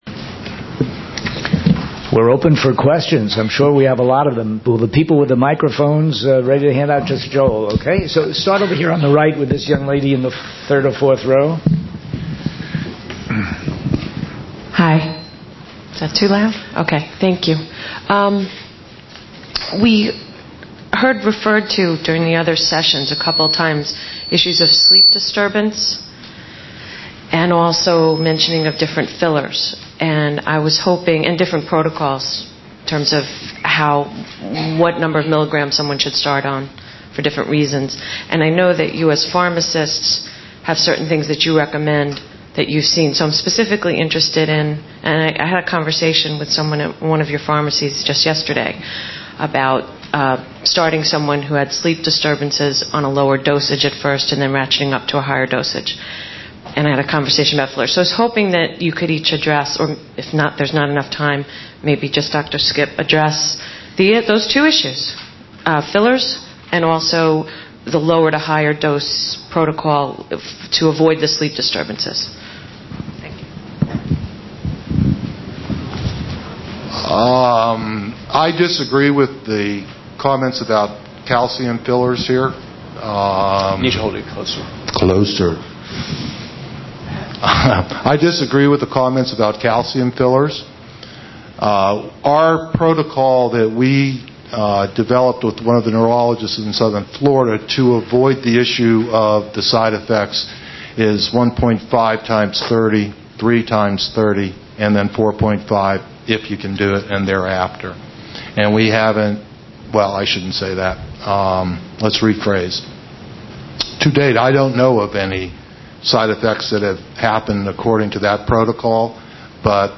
The First Annual LDN Conference (2005) - Low Dose Naltrexone